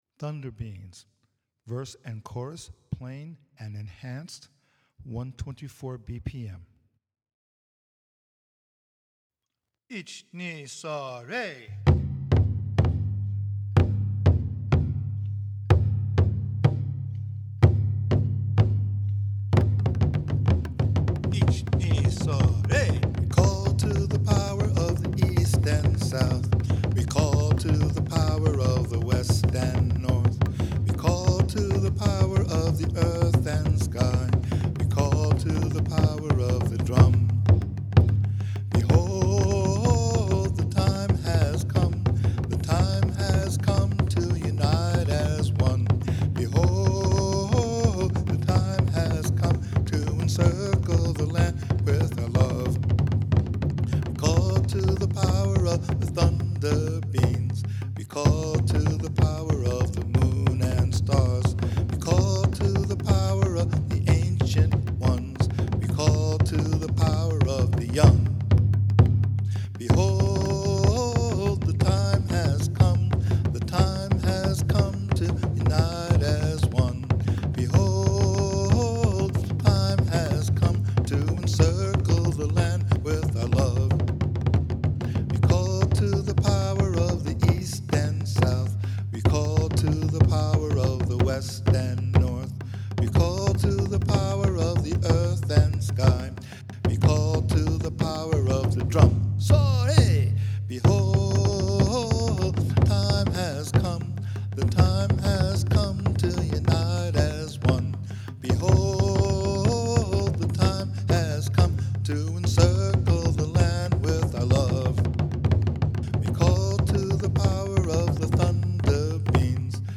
It is also quite upbeat and energetic.
Multi-track recording of the basic arrangement of the Verse and Chorus of Kaminari Tamashi at 124 BPM, closer to performance pace, chu-daiko, Odaiko, and shimedaiko with Vocals and Coda. Plain and “embellished” versions of the Verse and Chorus are demonstrated.